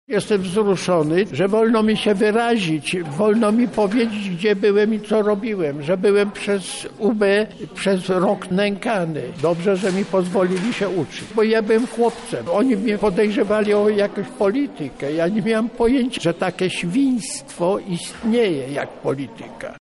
Częścią projektu pod tym tytułem było dzisiejsze spotkanie z osobami, które brały udział w Powstaniu Warszawskim. Aula Centrum transferu Wiedzy KUL wypełniła się młodymi ludźmi, których łączy zainteresowanie historią Polski.